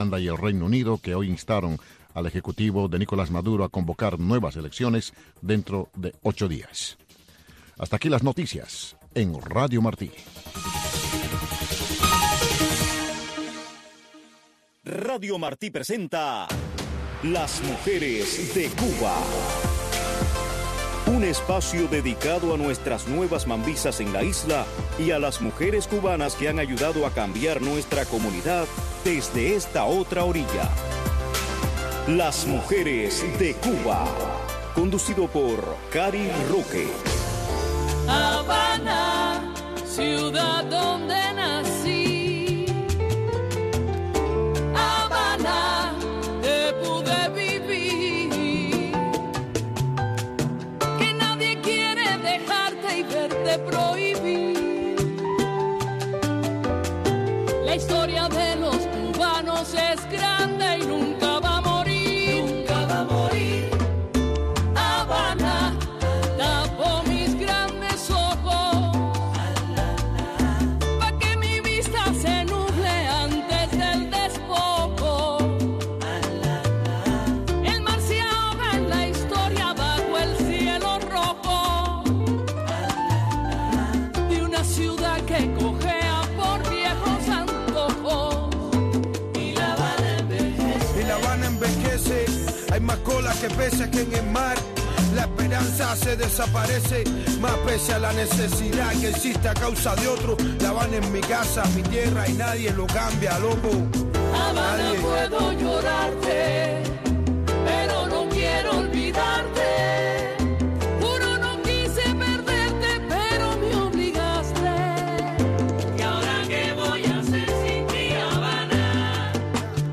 Un programa narrado en primera persona por las protagonistas de nuestra historia.